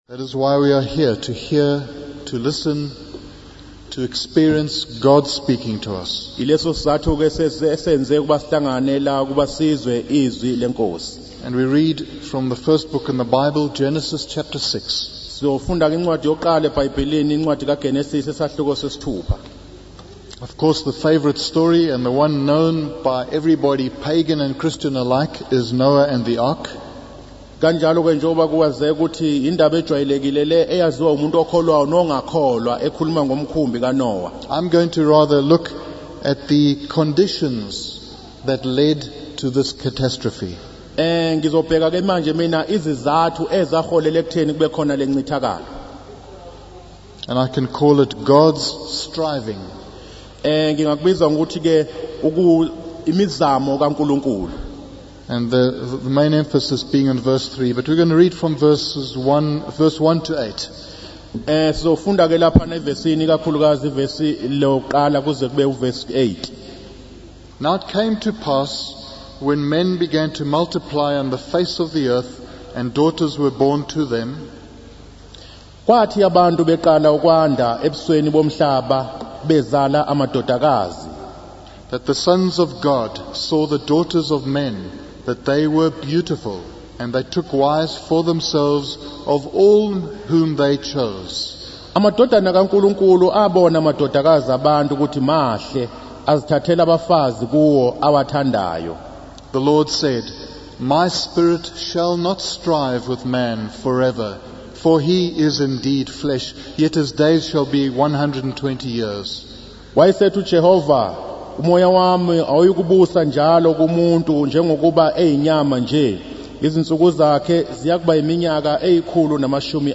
In this sermon, the preacher discusses the prevalence of violence and corruption in society, using the example of a recent rape incident at a university hostel. He emphasizes that violence begins in the heart and includes not only physical acts but also anger and hatred towards others.